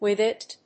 アクセントwíth‐it